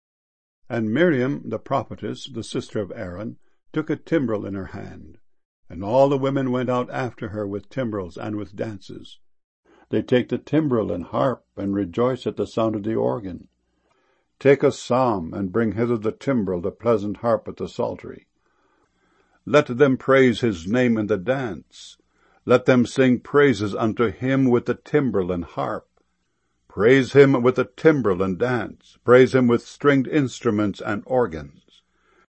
timbrel.mp3